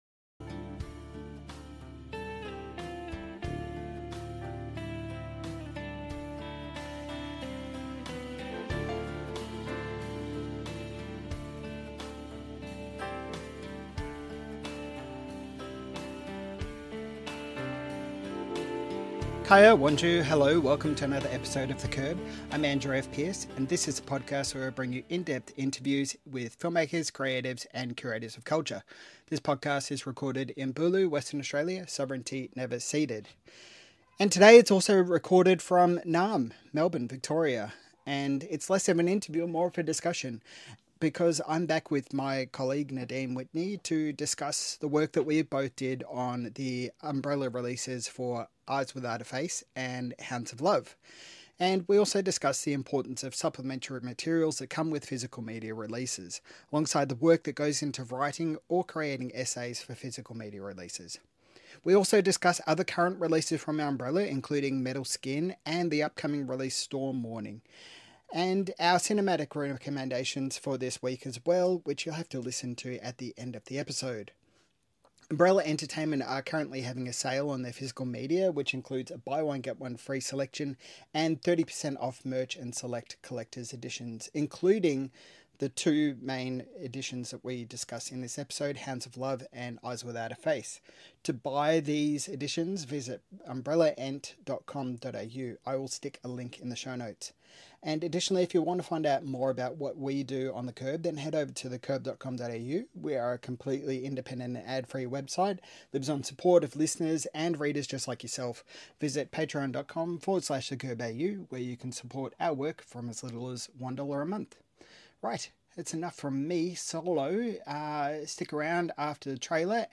And we're back with another review discussion with myself